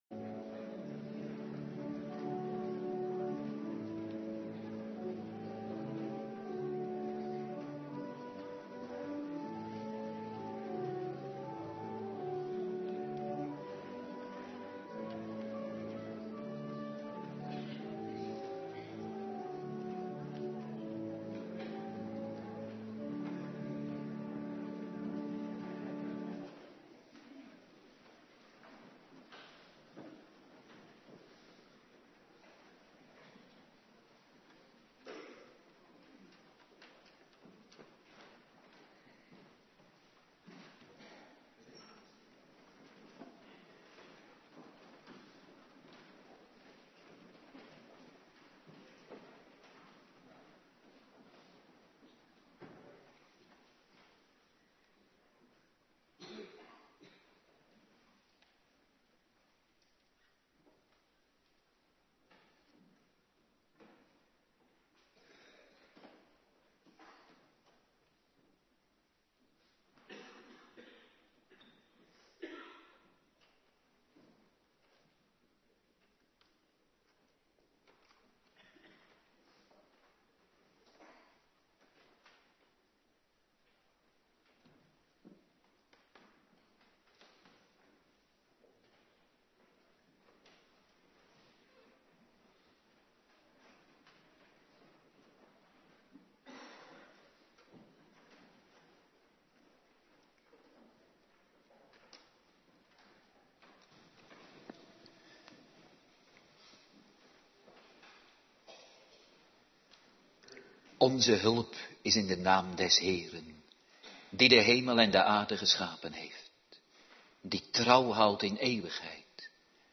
Morgendienst
09:30 t/m 11:00 Locatie: Hervormde Gemeente Waarder Agenda